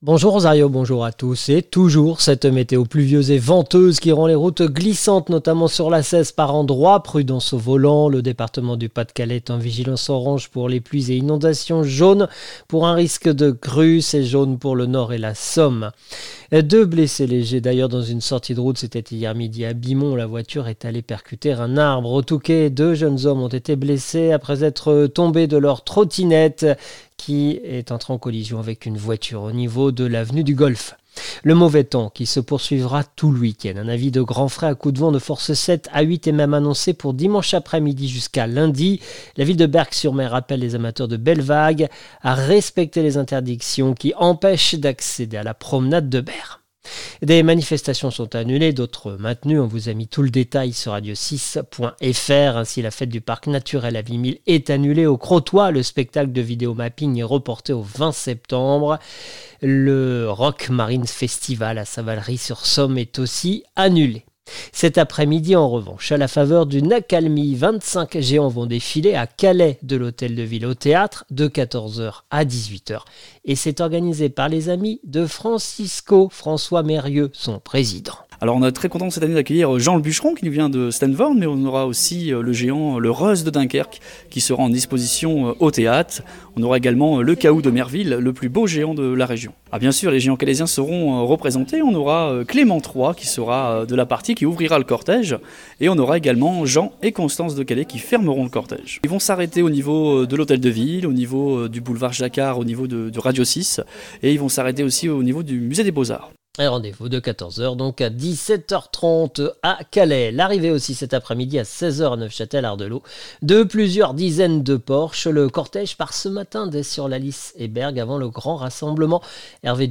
Le journal du samedi 13 septembre 2025